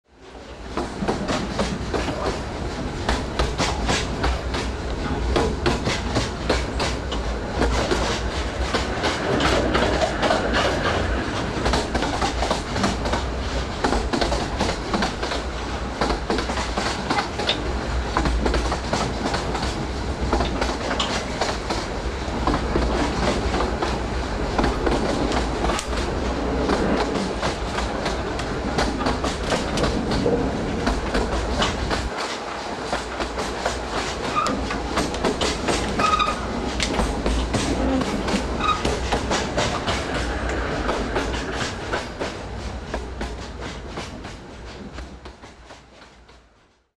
esa-misiones-cataratas-del-iguazu-tren-ecologico.mp3